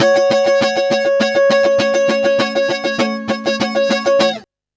100FUNKY10-L.wav